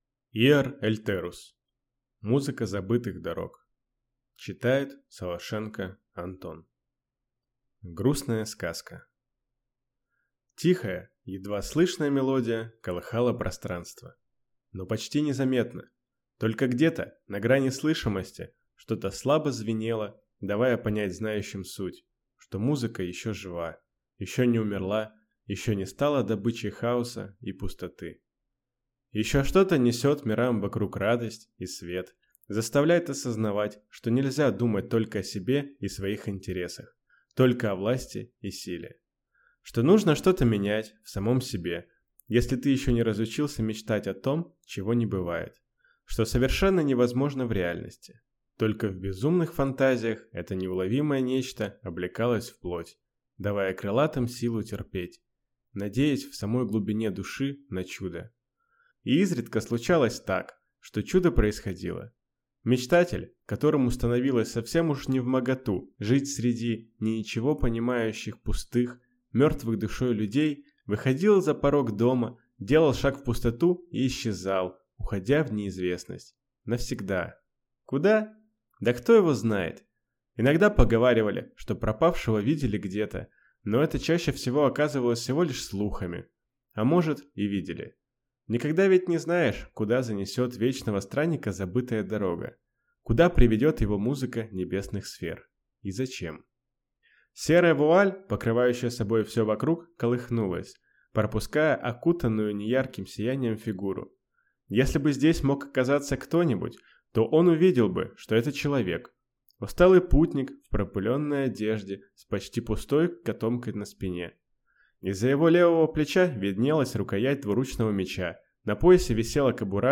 Аудиокнига Музыка забытых дорог | Библиотека аудиокниг